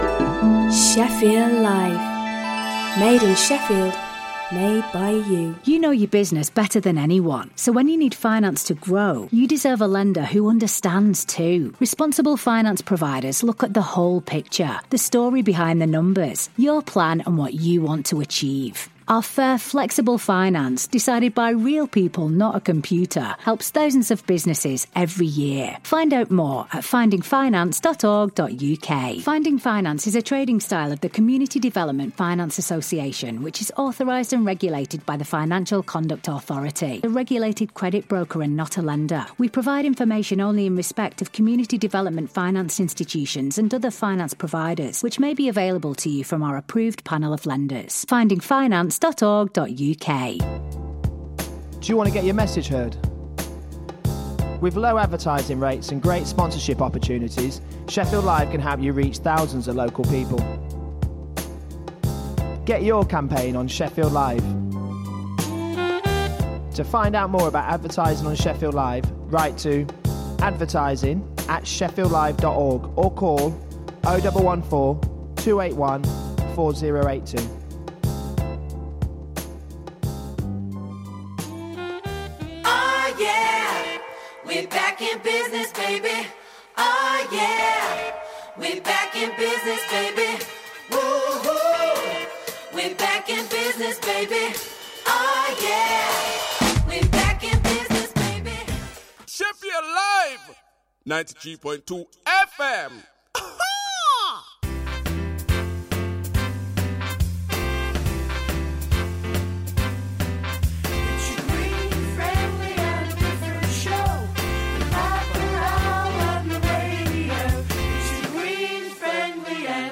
Information about radical environmental projects, innovative regeneration activities, views on the city’s development and off-the-wall cultural projects with a wide range of music from across the world.